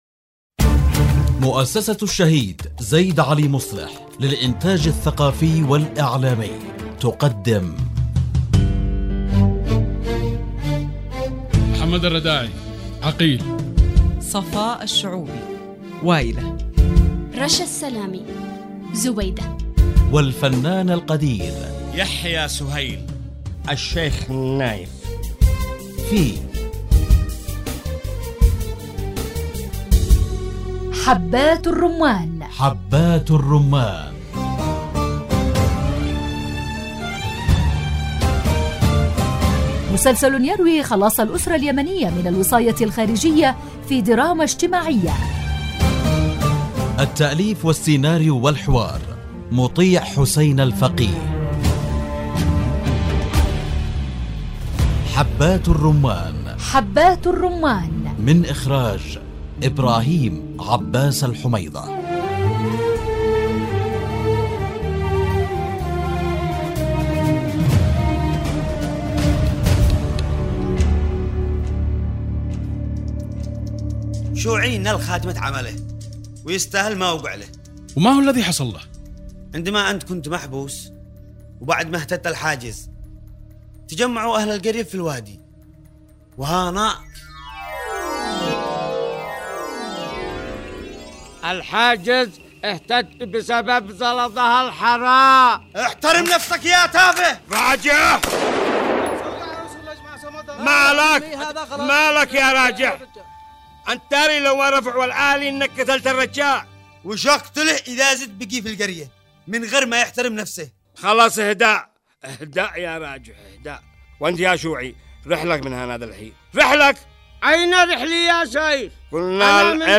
الدراما